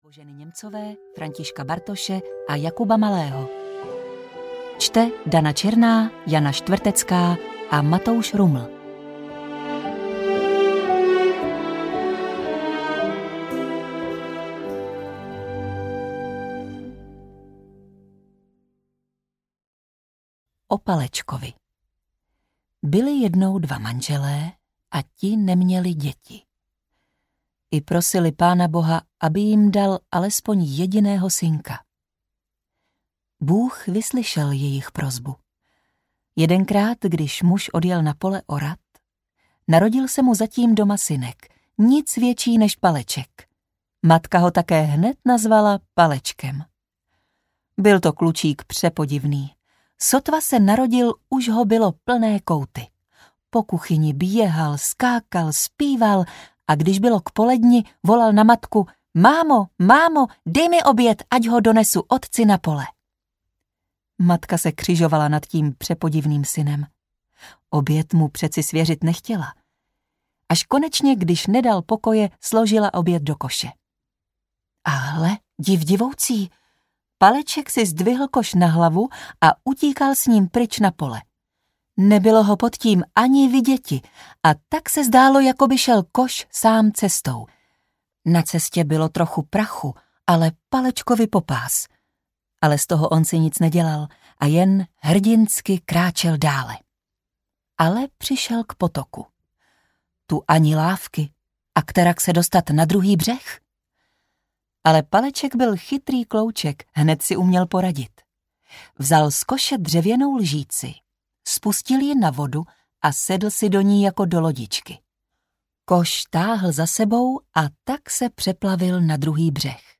Audio knihaČeské pohádky pro nejmenší
Ukázka z knihy